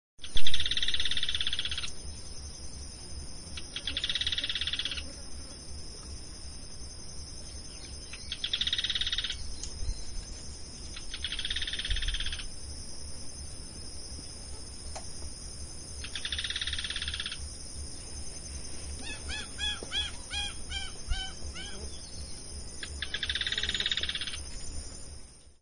Yellow-chinned Spinetail (Certhiaxis cinnamomeus)
Life Stage: Adult
Location or protected area: Parque Nacional Río Pilcomayo
Condition: Wild
Certainty: Observed, Recorded vocal